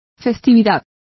Complete with pronunciation of the translation of festivity.